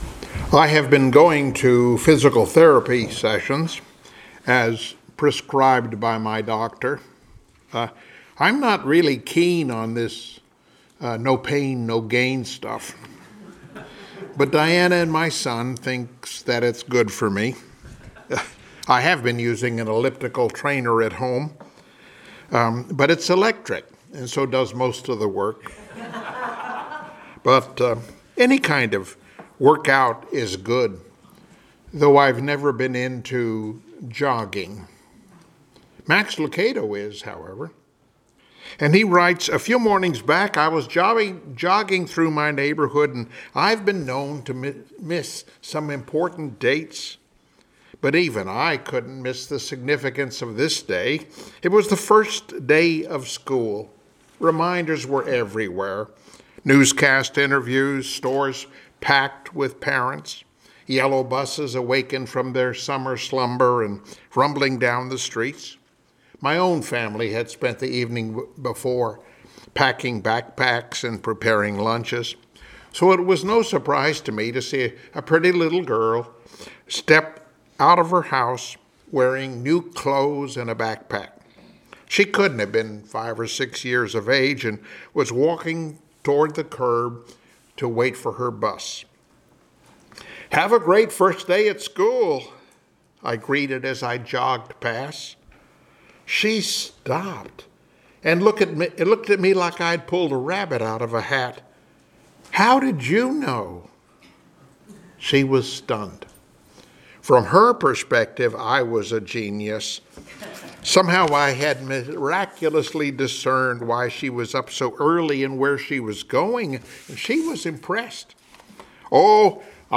Passage: Psalm 9:1-5 Service Type: Sunday Morning Worship